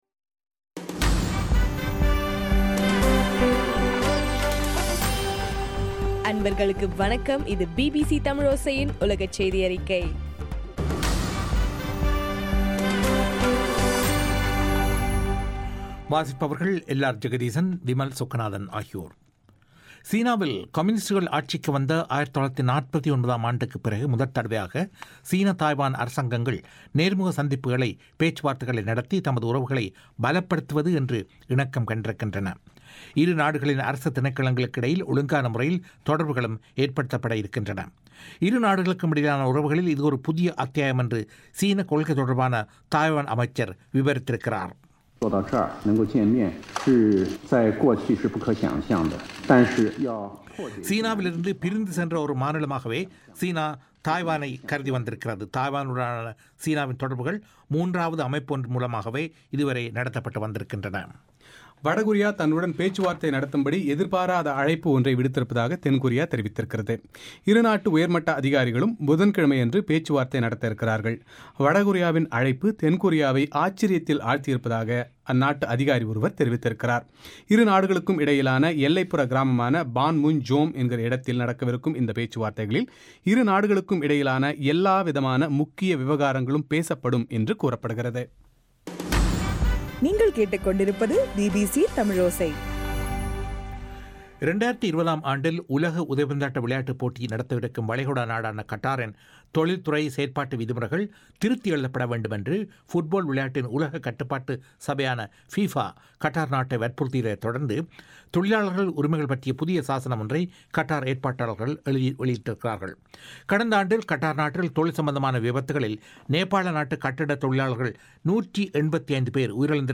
இன்றைய ( பிப் 11)பிபிசி தமிழோசை உலகச் செய்தி அறிக்கை